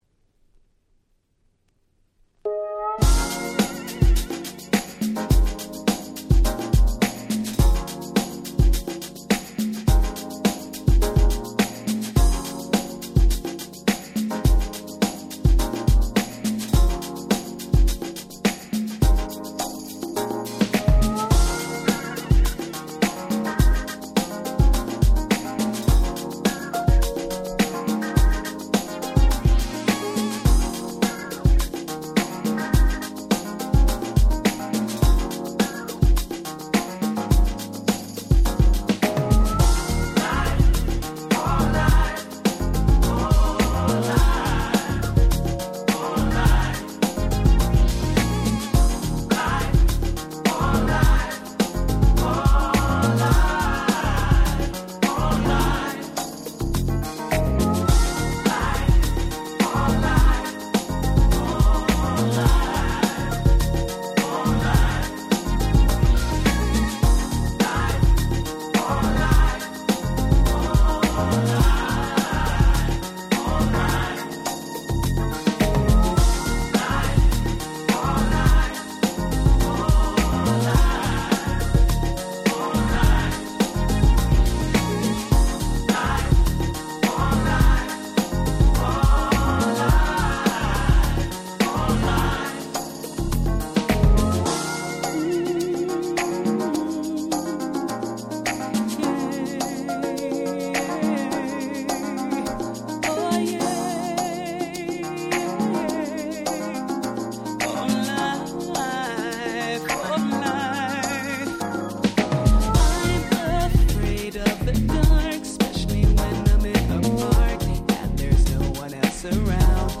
98' Smash Hit UK R&B !!